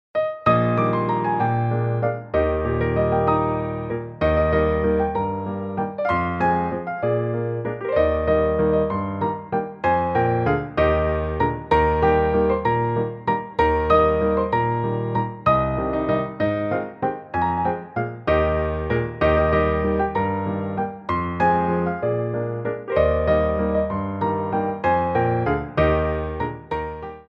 Grands Battements
3/4 (16x8)